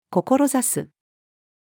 志す-female.mp3